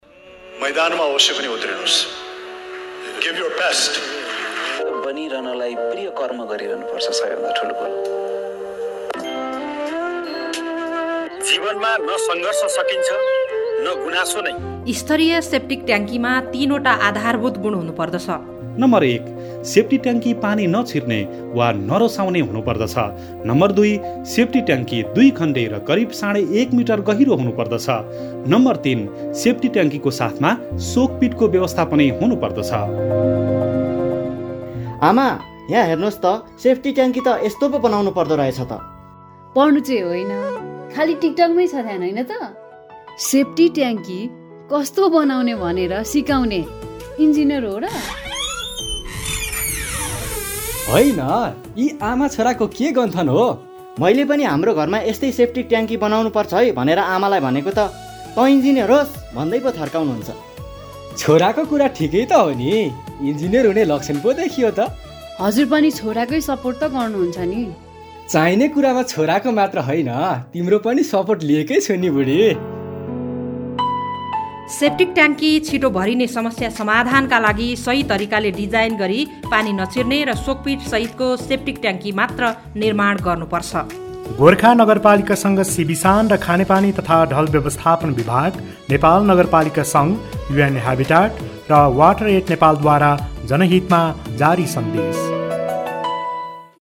काठमाडौः नगरव्यापी समावेशी सरसफाइ सम्बन्धी जनहितकारी रेडियो सन्देशहरु उत्पादन गरिएको छ ।